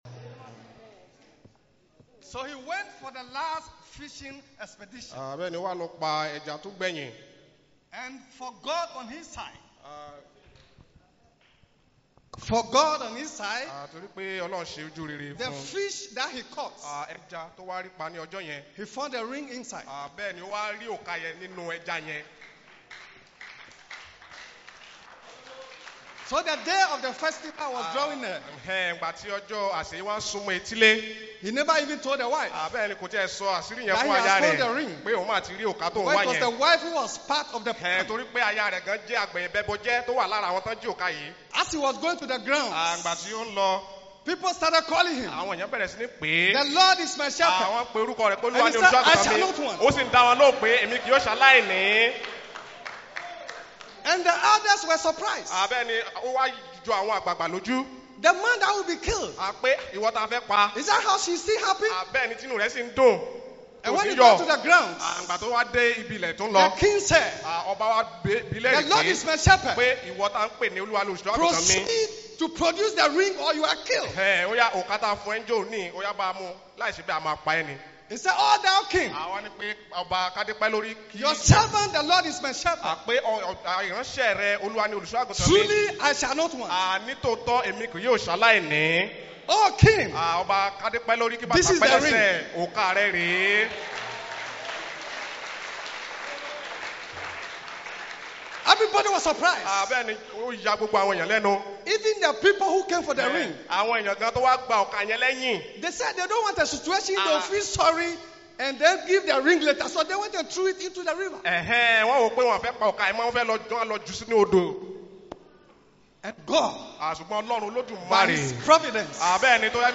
2023 BELIEVERS' CONVENTION SAT.12-08-23_MORNING SERVICE_